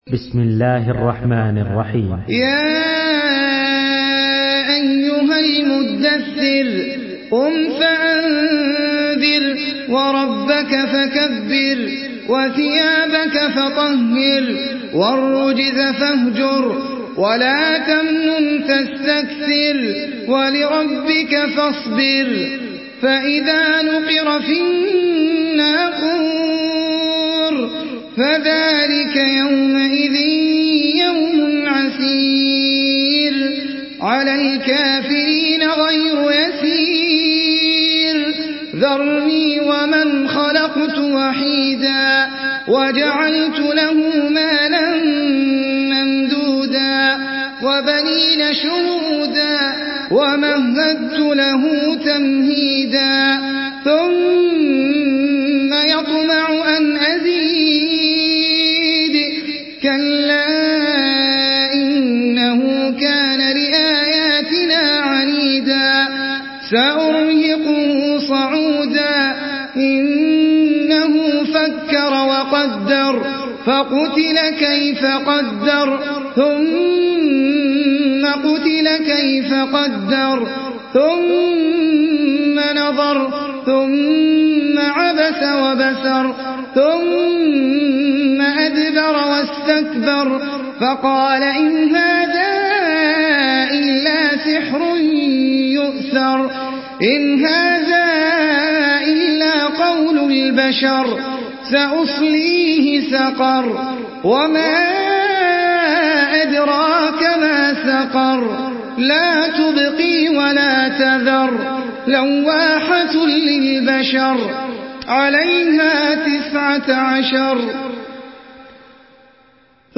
Surah Al-Muddathir MP3 by Ahmed Al Ajmi in Hafs An Asim narration.
Murattal Hafs An Asim